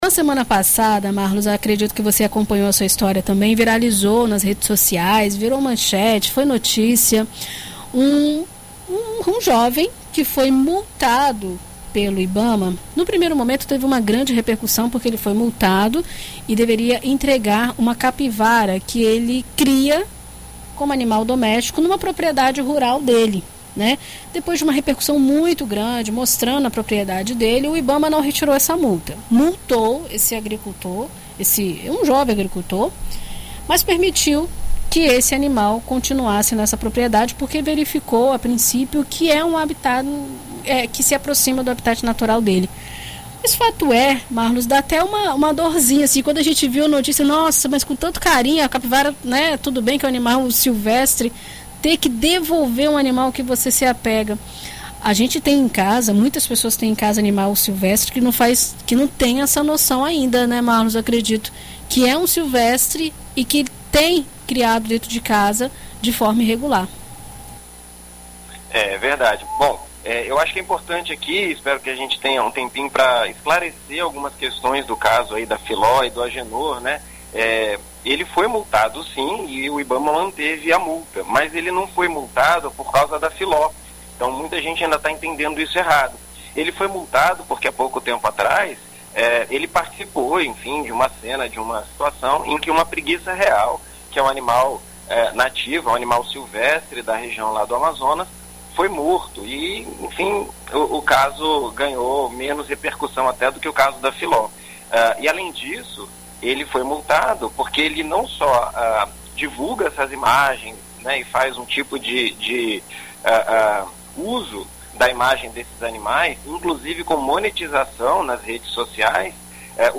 Em entrevista a BandNews FM Espírito Santo